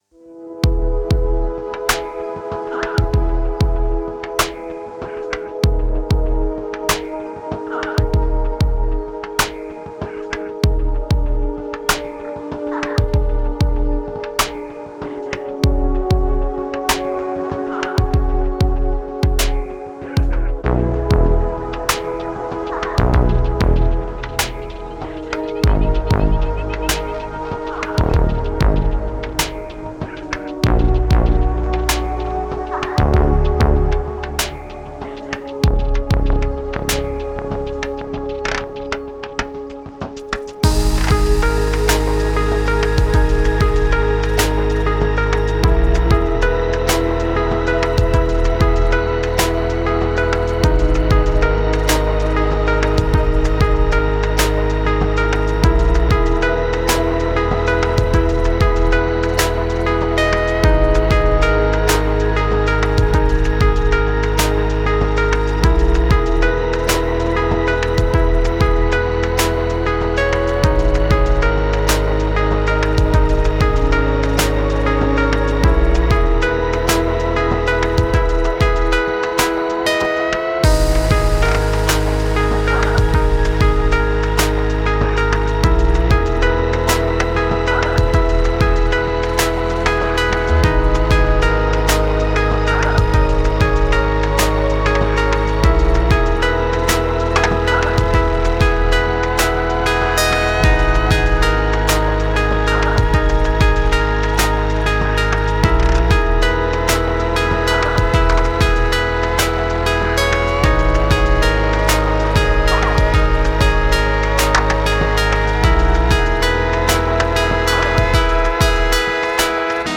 Cinematic Electronica